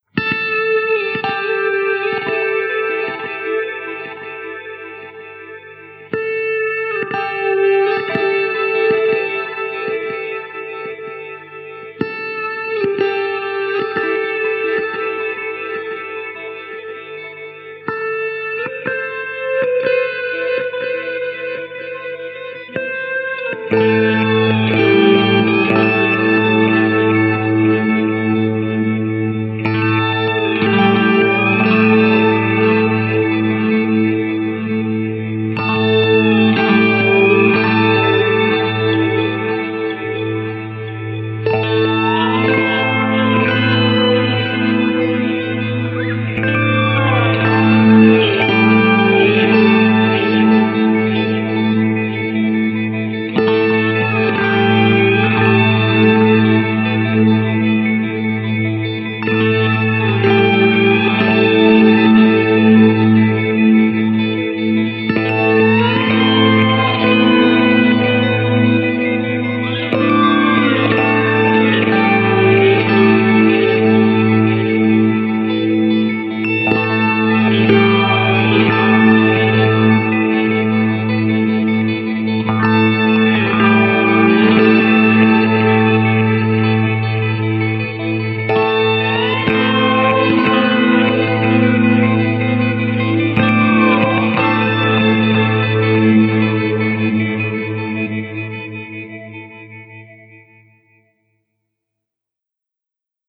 Sustained six string mutations.
Atmospheric uneasy suspenseful guitar ambience.